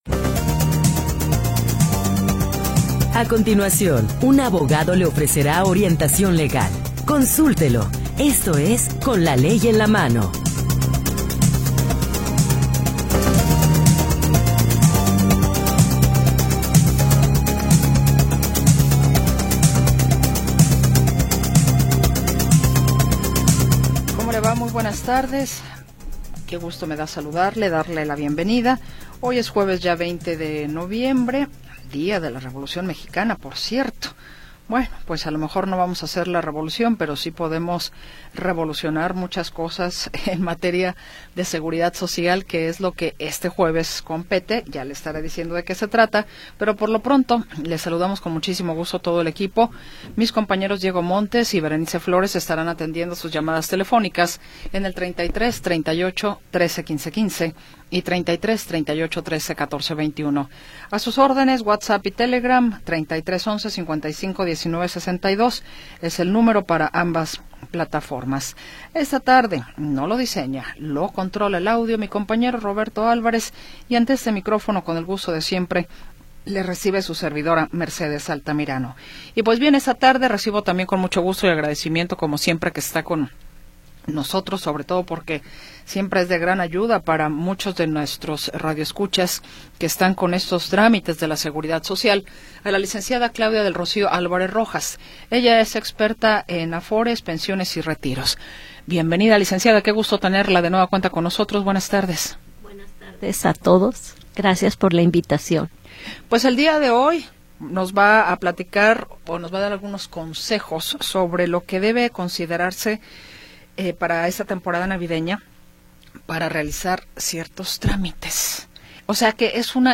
Orientación legal de jueces y abogados especialistas
Programa transmitido el 20 de Noviembre de 2025.